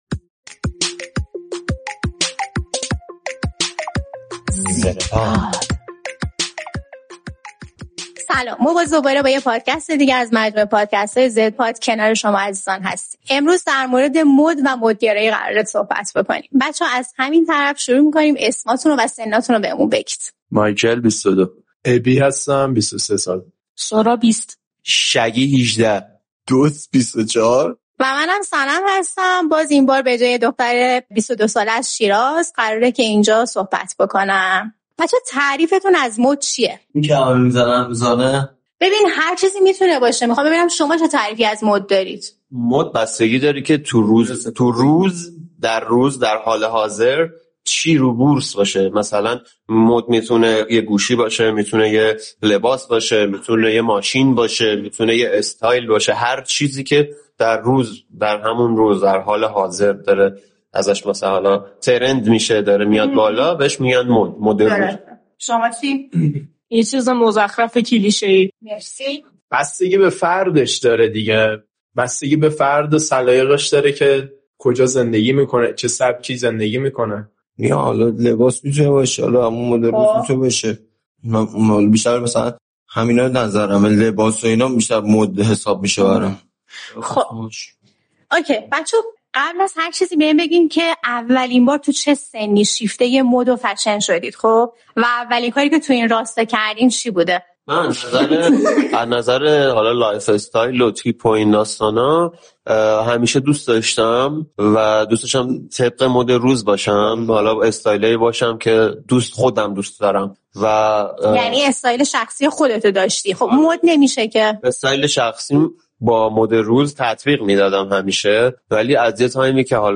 در این قسمت پادکست «زدپاد» گروهی از بچه‌های نسل زد در ایران درباره آنچه «فَشن» گفته می‌شود یا به قول نسل‌های گذشته «مُد روز» گفت‌وگو کردند.